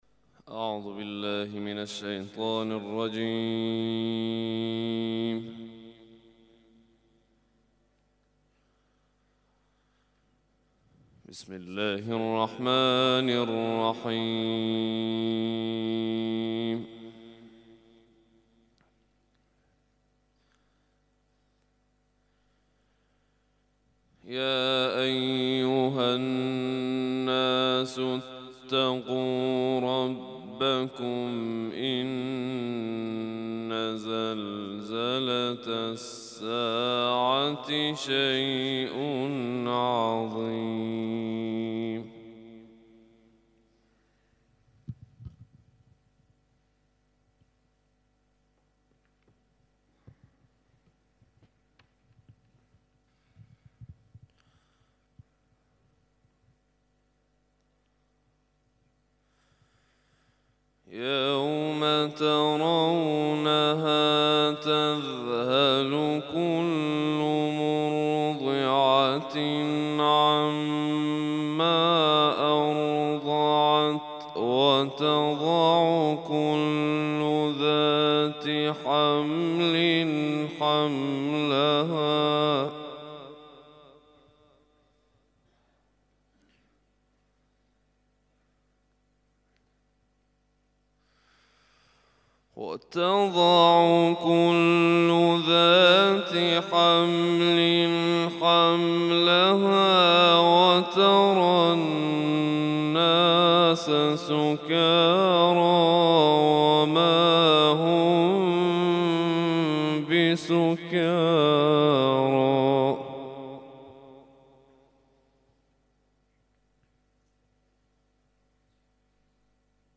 تلاوت صبح